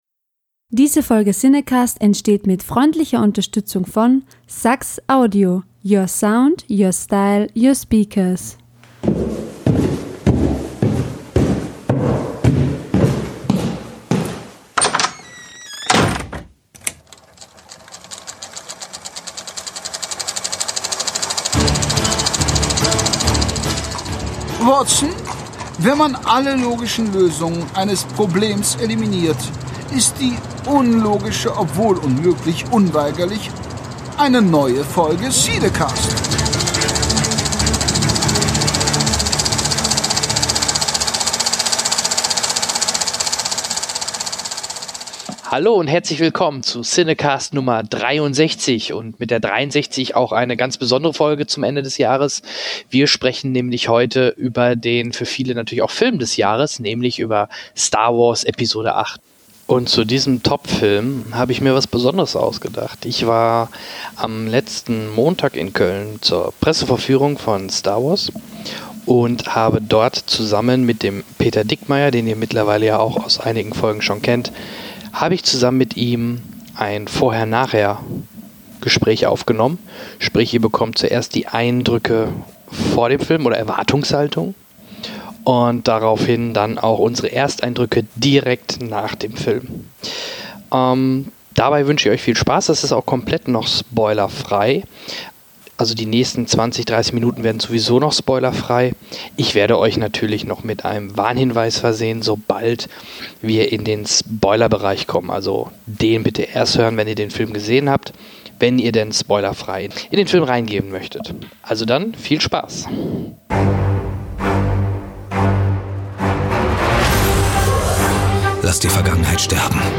Zur Folge 63 habe ich mir Unterstützung dazugeholt. Im ersten Part gibt es ein Live Bericht von der PV in Köln